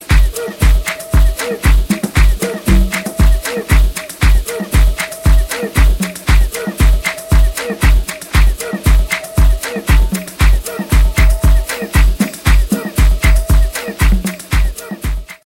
[AFRO&LATIN]